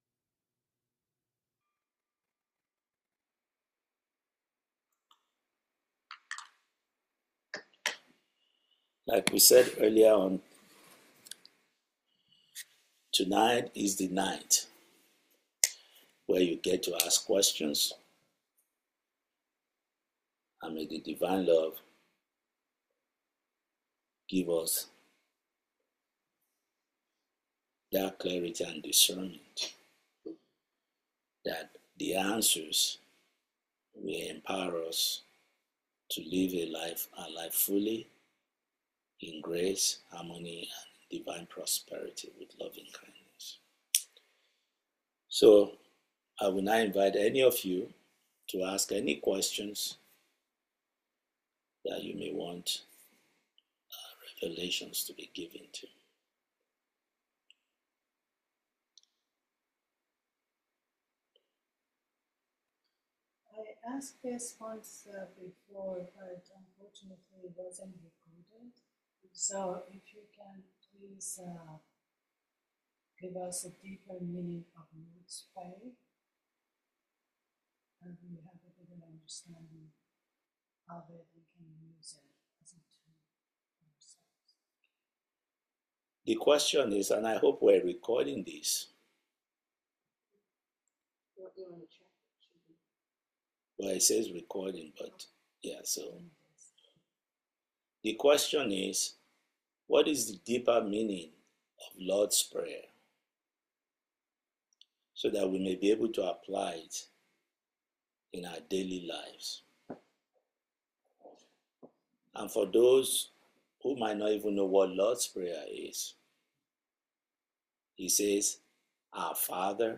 April 2025 Satsang